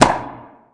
shot04.mp3